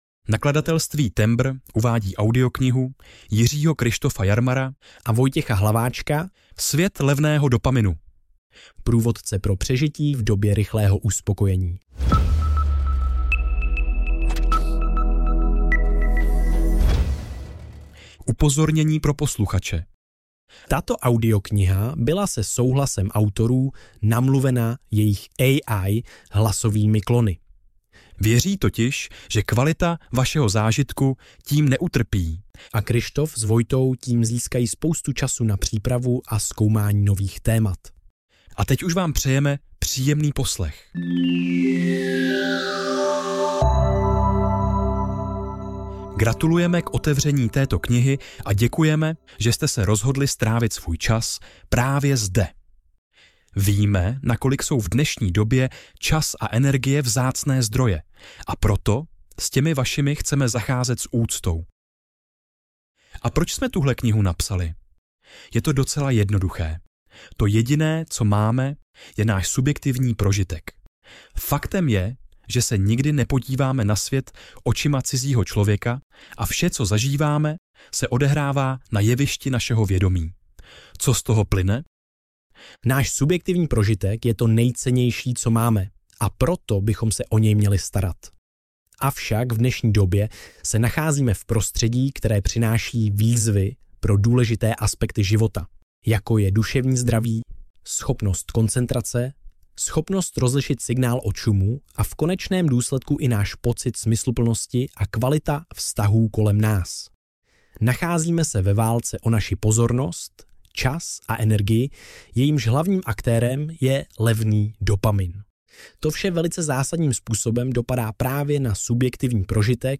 Svět levného dopaminu audiokniha
Ukázka z knihy
Kompletní zvukový obsah byl vytvořen pomocí umělé inteligence se souhlasem autorů.